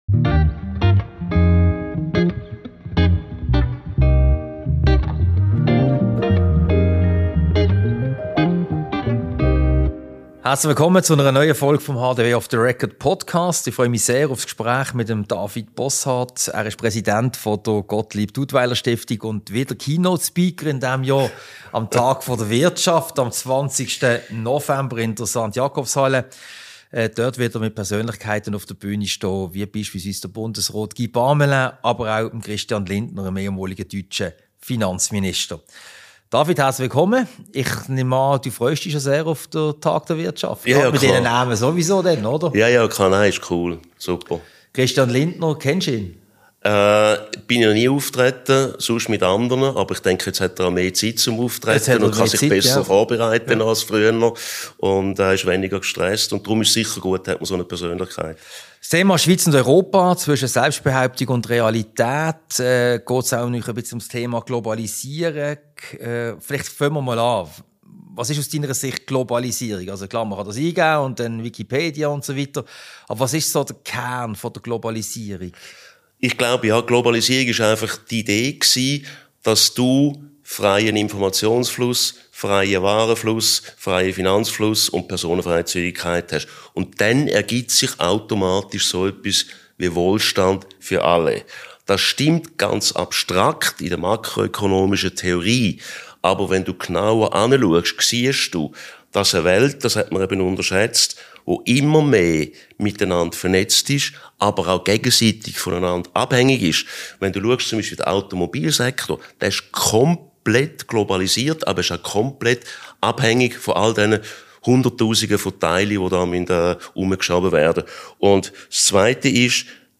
Ein Gespräch über die Schweiz und Europa – zwischen Selbstbehauptung, Realität und über Globalisierung. Diese Podcast-Ausgabe wurde als Video-Podcast im Sitzungszimmer Casablanca im Haus der Wirtschaft HDW aufgezeichnet.